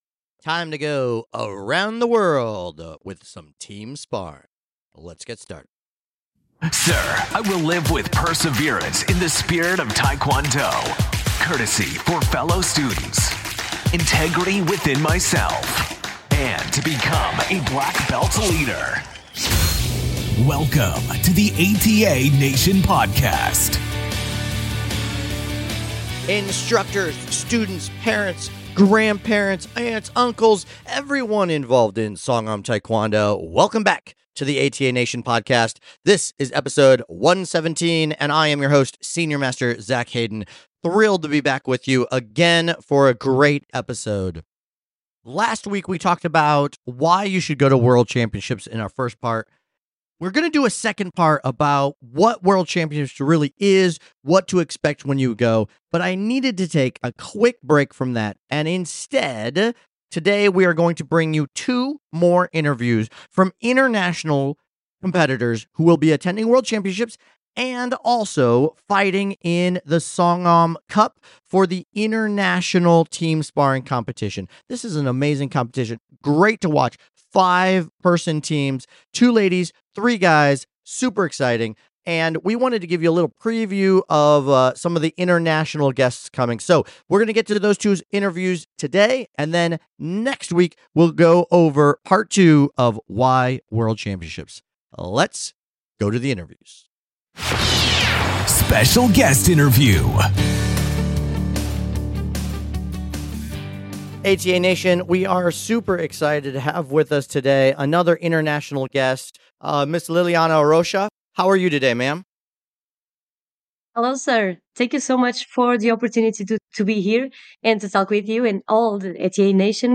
This event brings together 5 person international teams to compete against each other for the Songahm Cup. In this episode we interview two international athletes who will be fighting for their countries during the 2024 World Championship Songahm Cup.